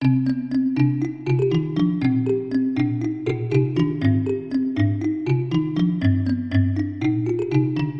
描述：电影/民谣风格的旋律 卡林巴打击乐器 铃铛什么的 xD
Tag: 120 bpm Cinematic Loops Bells Loops 1.35 MB wav Key : Unknown